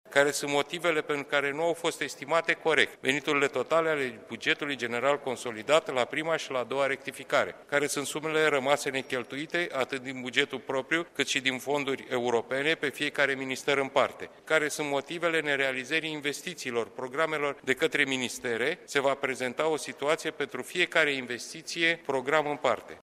Secretarul Biroului Permanent, Marcel Ciolacu, enumeră câteva dintre aspectele care trebuie urmărite de analiza specialiştilor Curţii de Conturi: